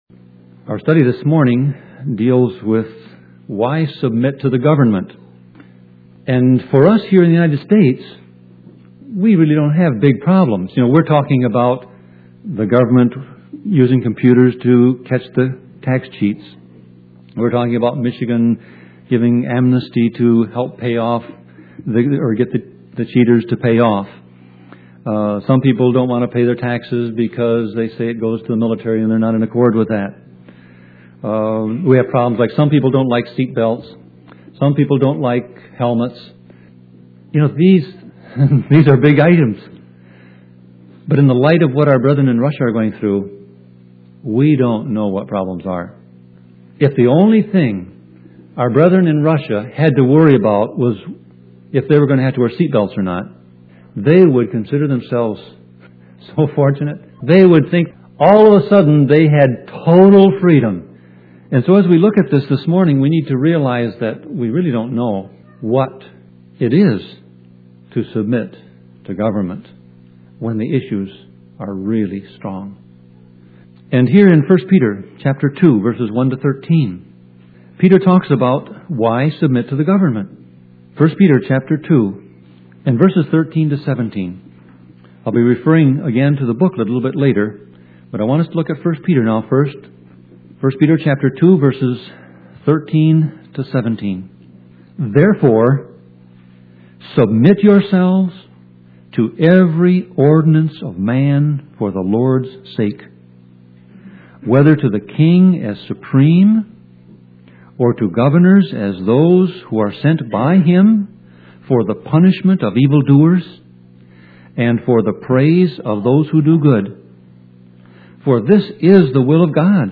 Sermon Audio Passage: 1 Peter 2:13-17 Service Type